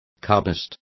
Complete with pronunciation of the translation of cubists.